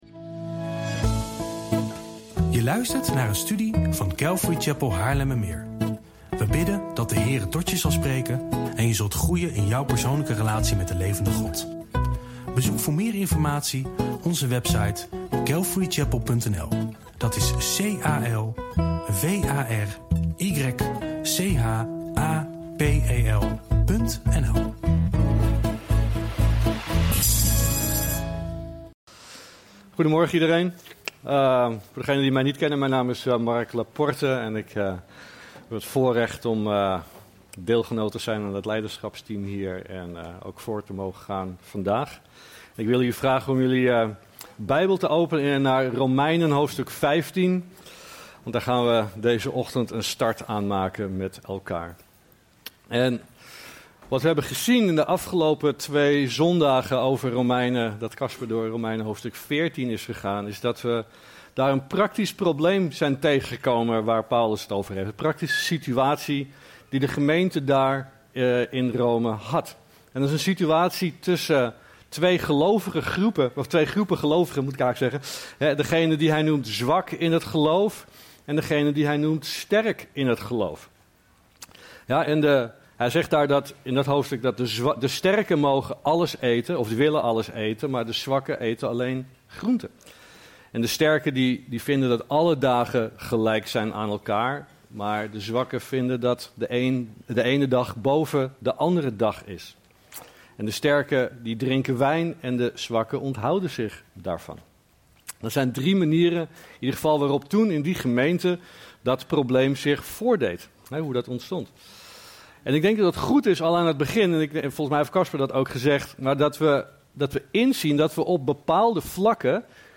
PREKENSERIE Studie Romeinen